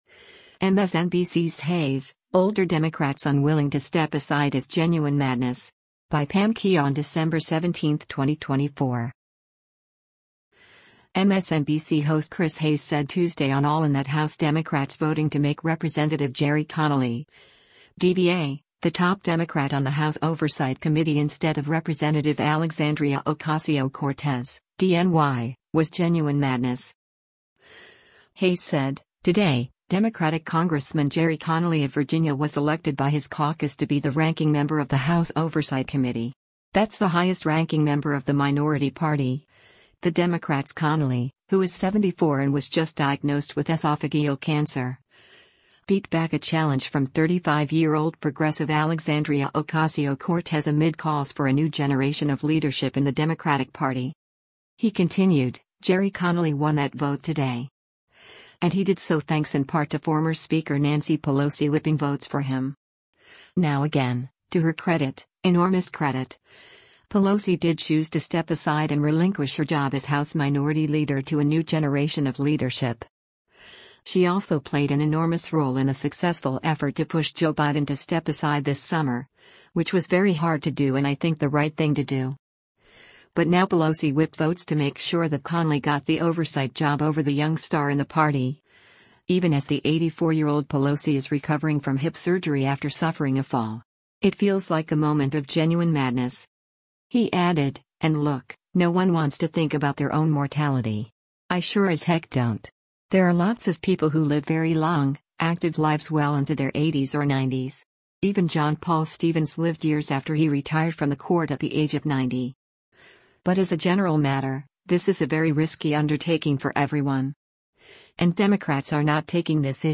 MSNBC host Chris Hayes said Tuesday on “All In” that House Democrats voting to make Rep. Gerry Connolly (D-VA) the top Democrat on the House Oversight Committee instead of Rep. Alexandria Ocasio-Cortez (D-NY) was “genuine madness.”